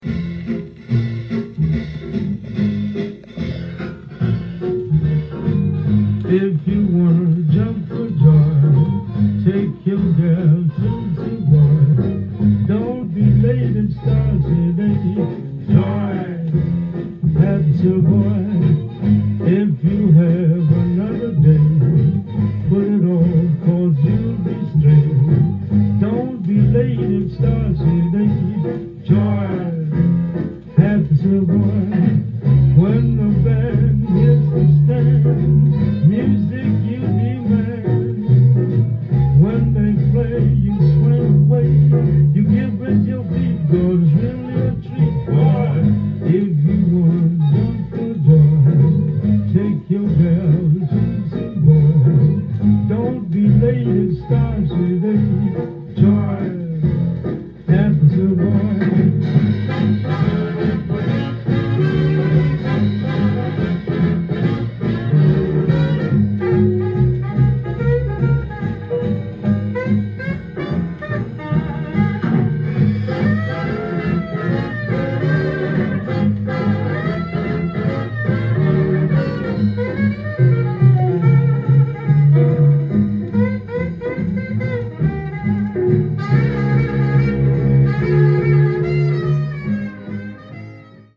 店頭で録音した音源の為、多少の外部音や音質の悪さはございますが、サンプルとしてご視聴ください。
インサート無し　盤が少し歪みだが視聴できます